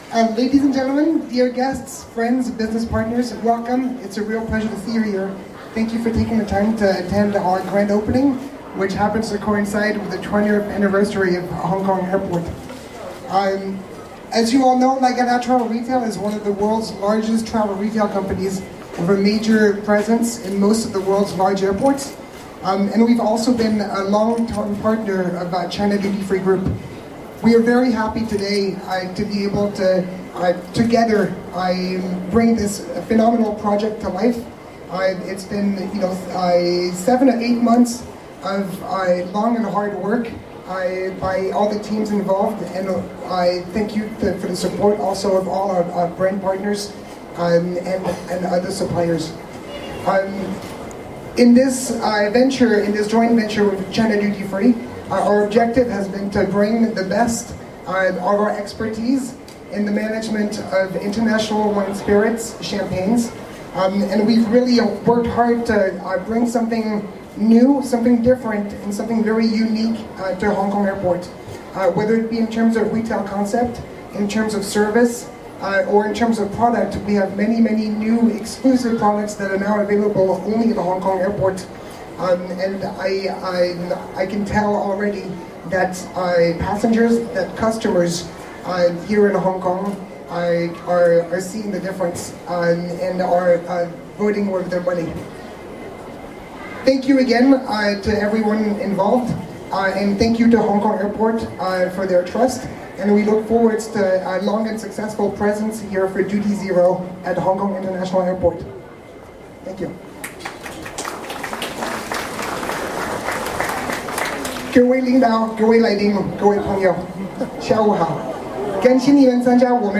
Duty Zero - Lagardere Interview